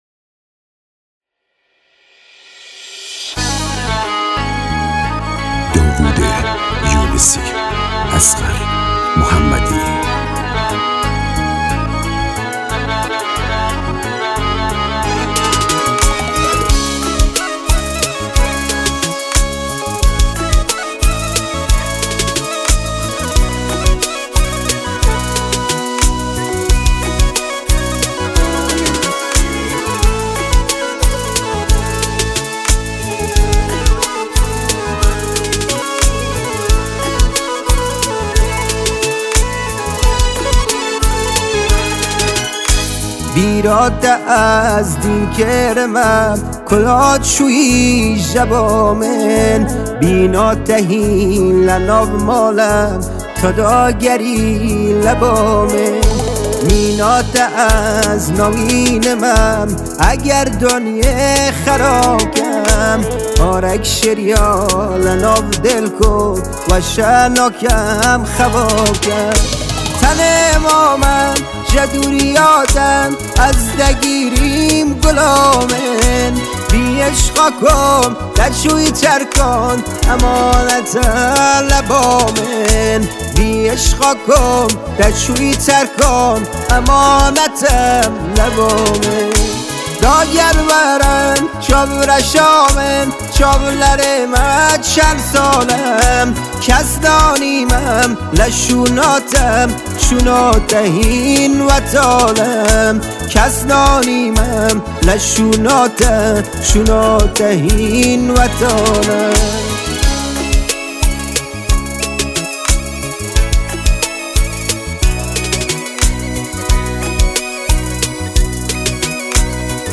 موزیک کرمانجی